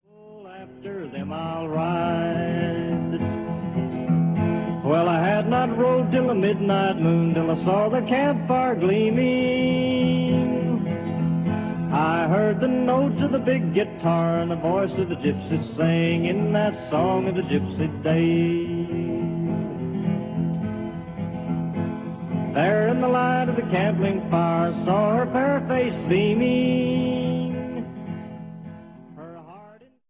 Recorded in New York, New York between 1944 and 1947.